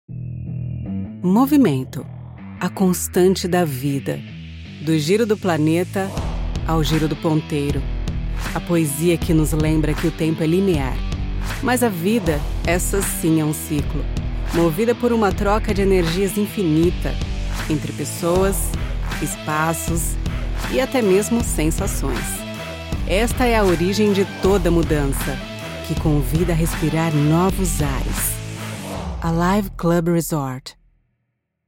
Her voice is pleasant and versatile and can help you create a unique and captivating listening experience for your audience.
I have a professional home studio with all the bells and whistles.